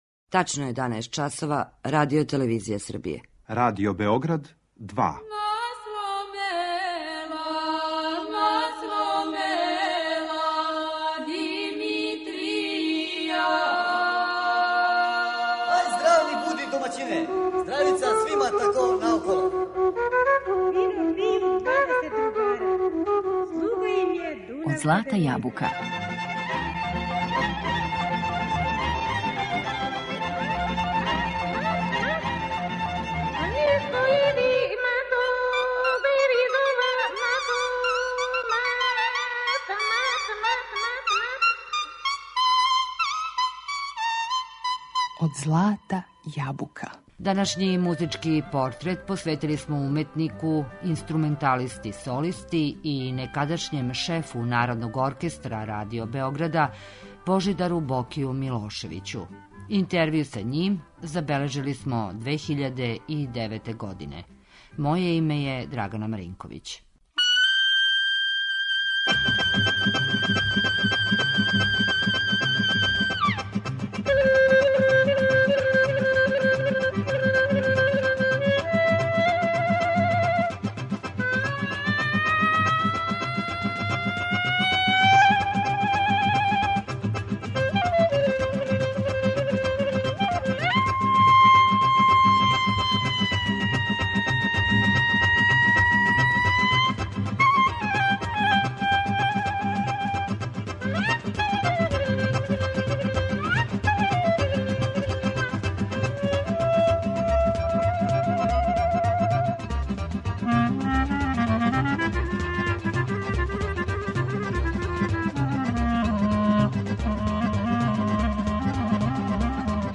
Интервју са уметником забележили смо 2009. године.